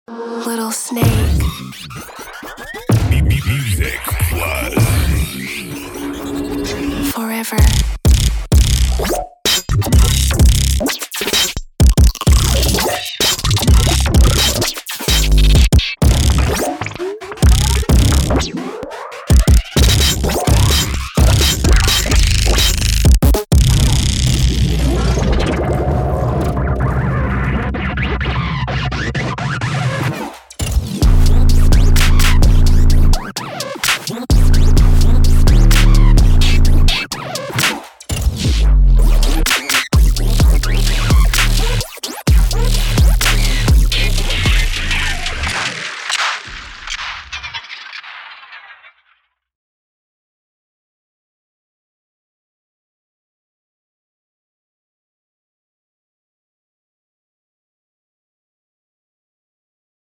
Hyperpop